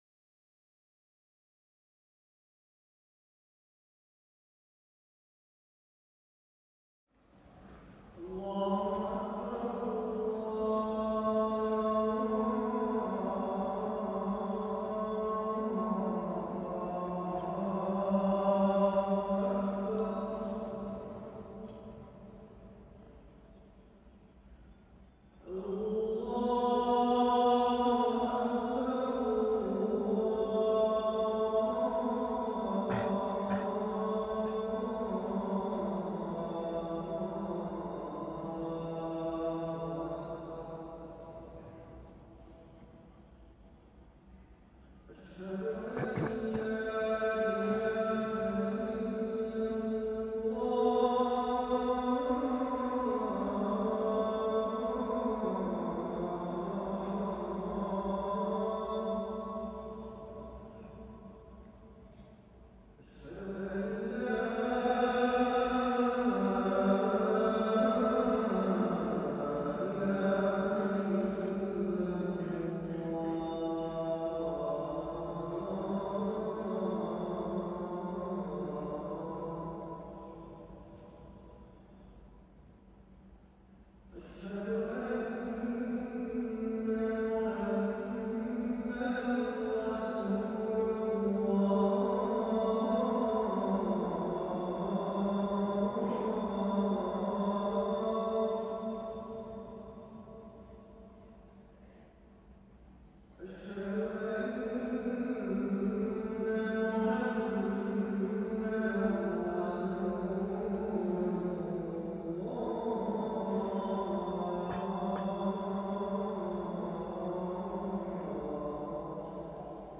الخبيئة الصالحة خطبة الجمعة - فضيلة الشيخ نبيل العوضي